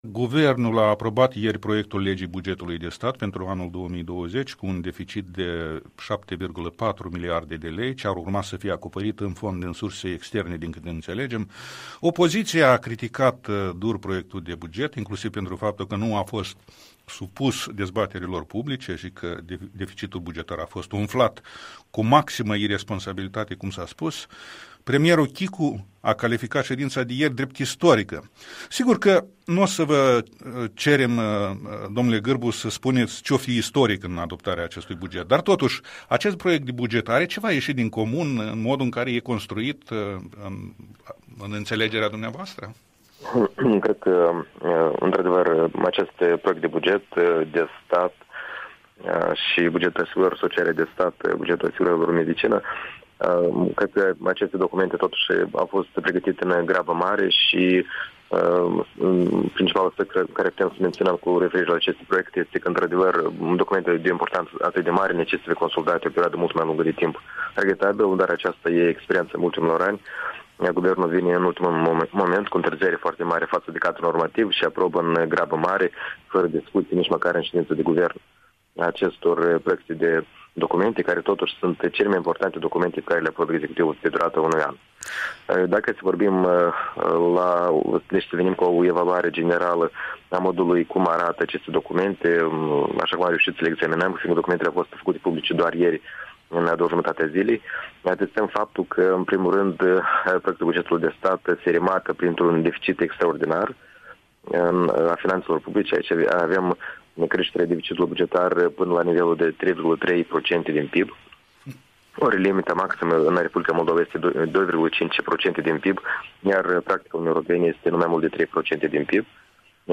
Interviul matinal